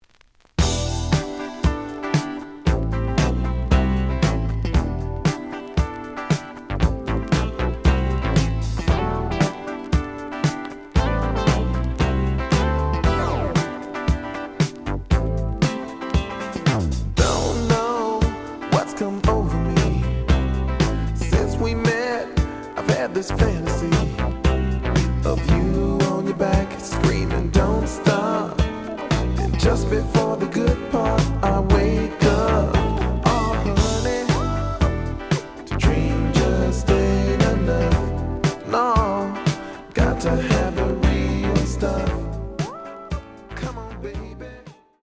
試聴は実際のレコードから録音しています。
The audio sample is recorded from the actual item.
●Format: 7 inch
●Genre: Funk, 80's / 90's Funk